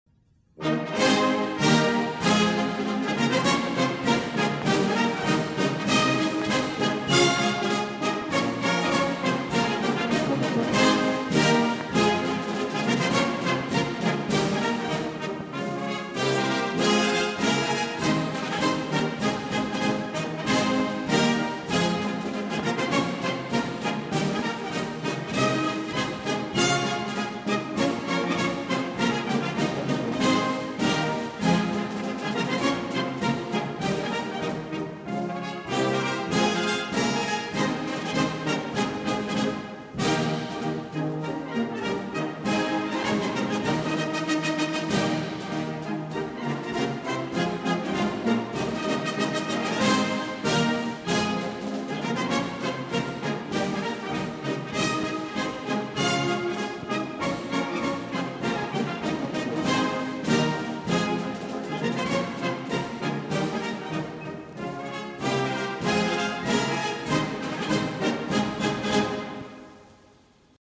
Музыка с Парада 1945 года